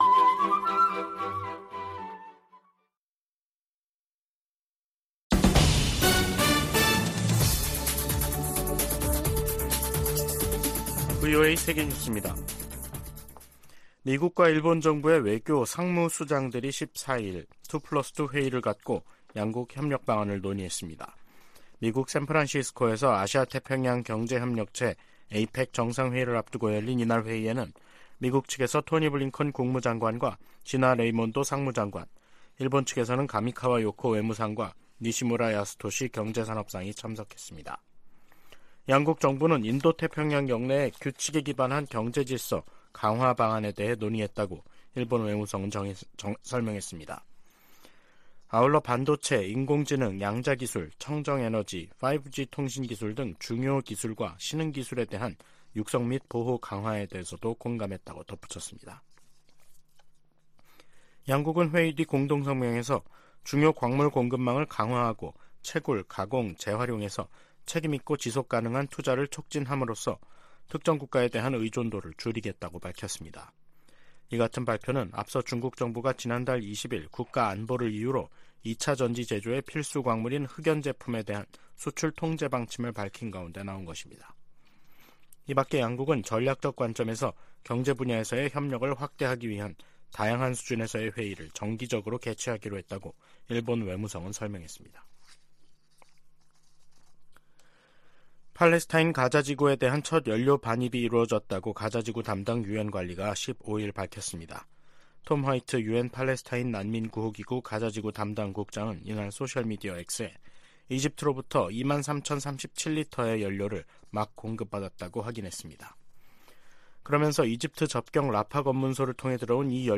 VOA 한국어 간판 뉴스 프로그램 '뉴스 투데이', 2023년 11월 15일 3부 방송입니다. 미국과 한국, 일본의 외교수장들이 미국에서 만나 중동 정세, 북한의 러시아 지원, 경제 협력 확대 등을 논의했습니다. 백악관은 조 바이든 대통령이 미중 정상회담과 관련해 대결과 외교 모두 두려워하지 않을 것이라고 밝혔습니다. 북한은 신형 중거리 탄도미사일, IRBM에 사용할 고체연료 엔진 시험을 성공적으로 진행했다고 밝혔습니다.